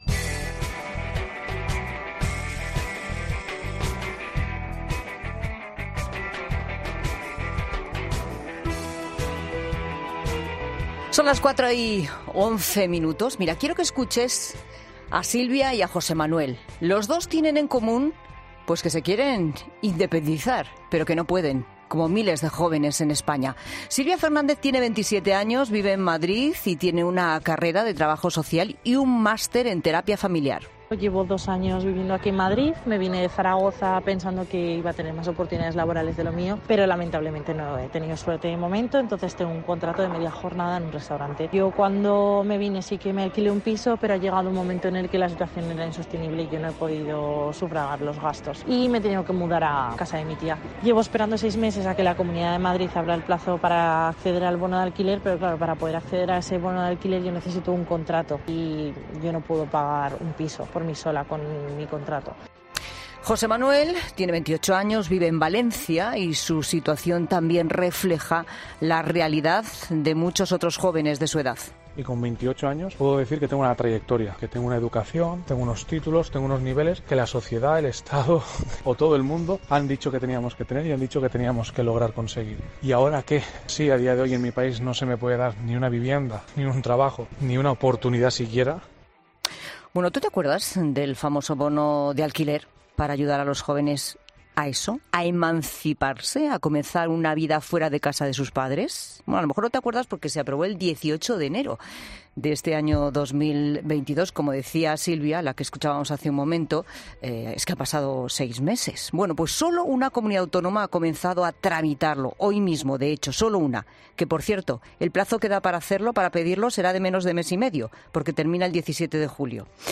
NO. Así, tajante ha respondido a la pregunta de Pilar Cisneros en 'La Tarde'